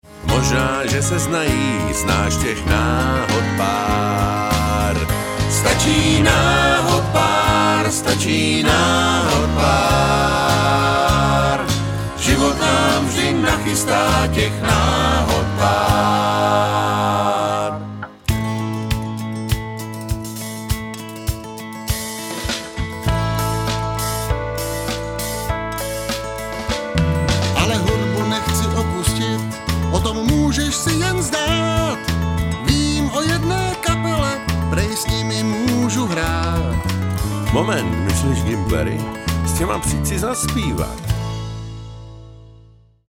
Sólový zpěv